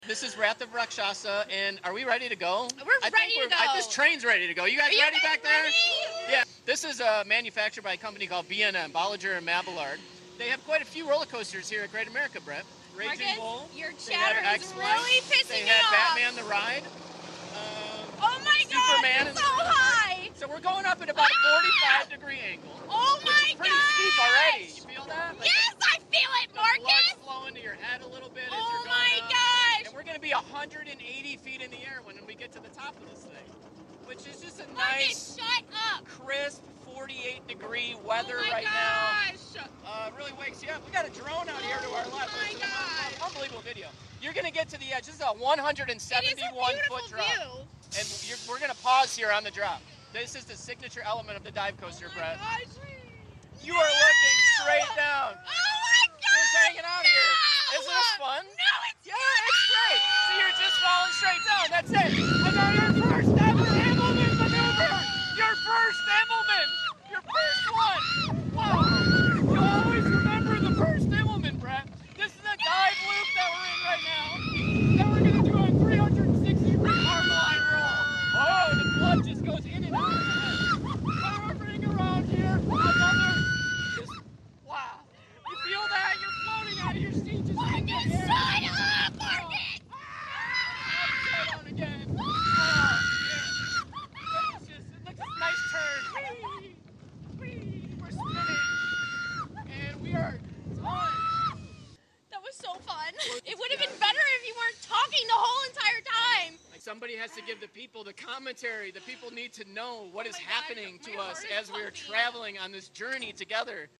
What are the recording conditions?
For some reason, my co-workers don’t seem to appreciate my on-ride commentary all the time.